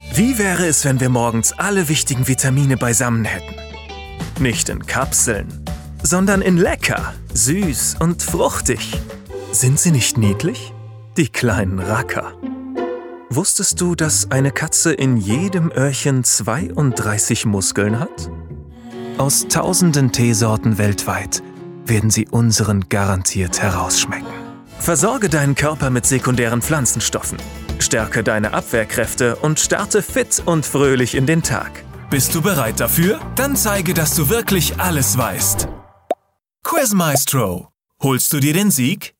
Mi voz puede ser cálida, entusiasta, auténtica y...
Fresco
Confiable
Cálido